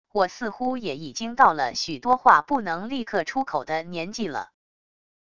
我似乎也已经到了许多话不能立刻出口的年纪了wav音频生成系统WAV Audio Player